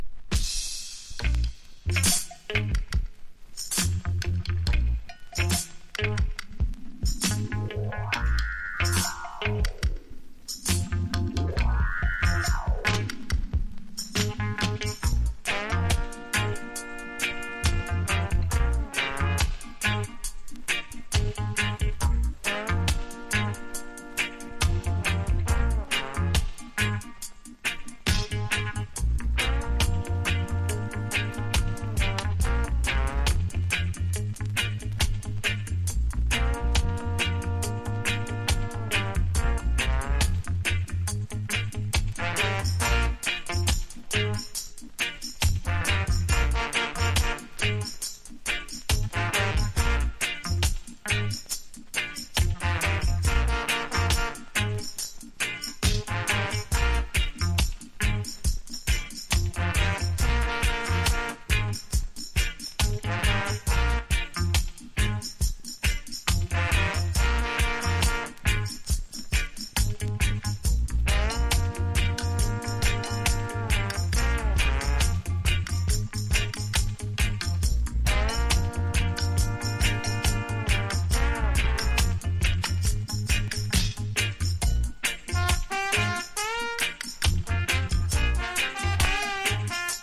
MONDO# ROOTS# SKA